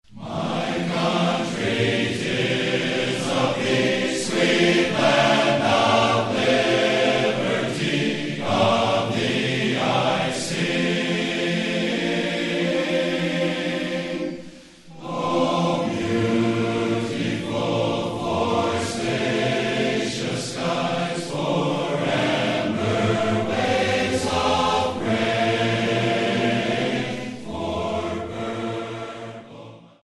Recorded on August 23, 2003 at Francesville, Indiana